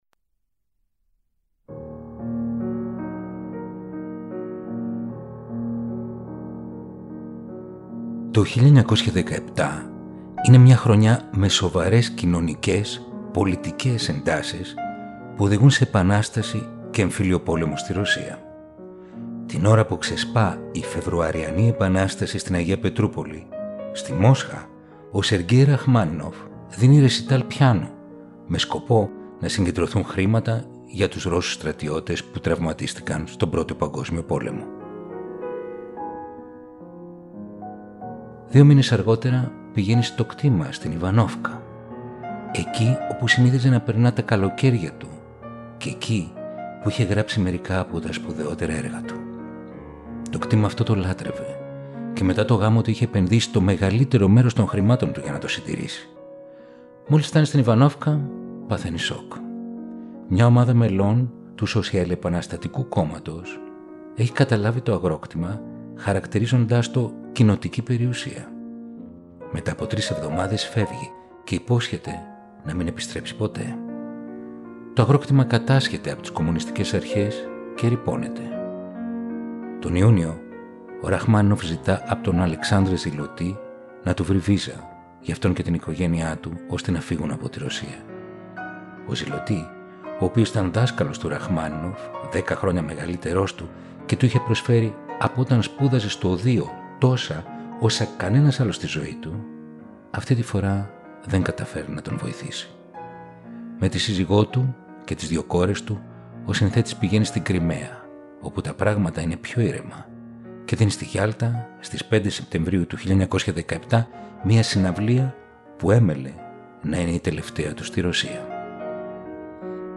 150 χρόνια από τη γέννηση του Σεργκέι Ραχμάνινοφ. Έργα για πιάνο και ορχήστρα.